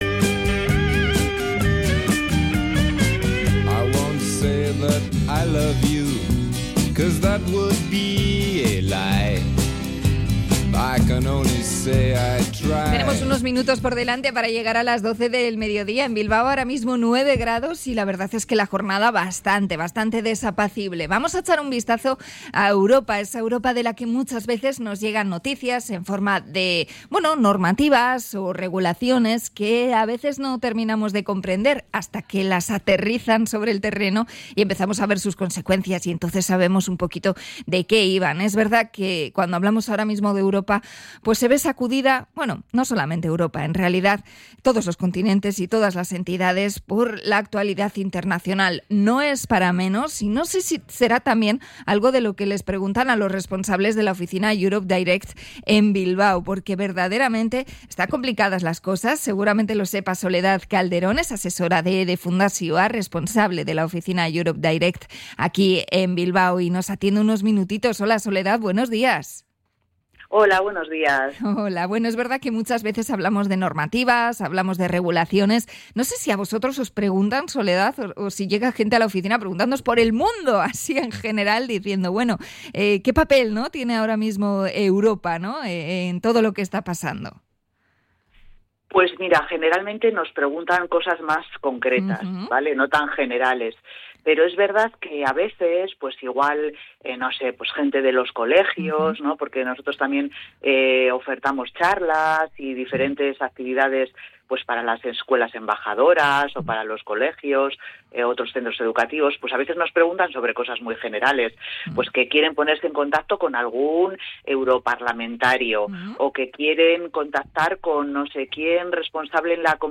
Visita mensual a la radio